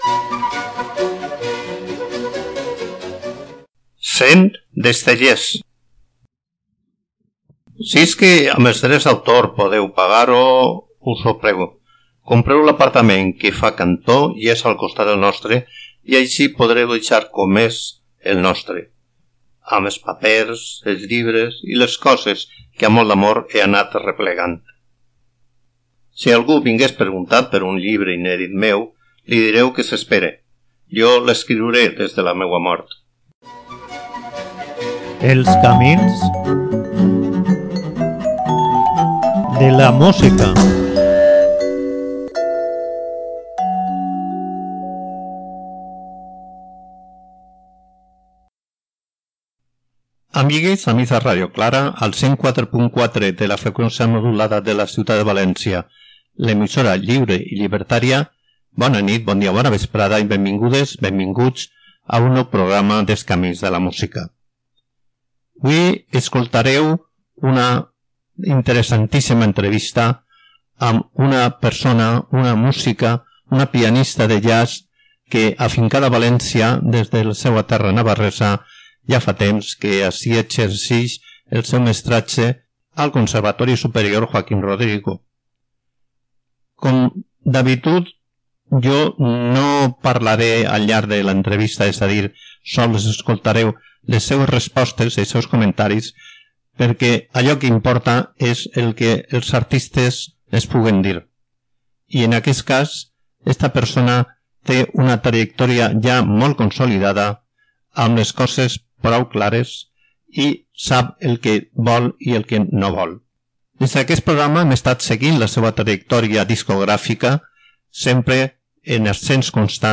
L'entrevista que fa d'acompanyament a la música va resultar d'una enorme frescor i sinceritat; una persona que té algunes coses que dir i altres... que no vol dir de forma expressa, però que es poden entendre enmig les seues paraules (i la seua música). Sempre amb l'alè del jazz com a rerefons, el treball conté un homenatge a Brahms passat pel sedàs de la seua personalitat.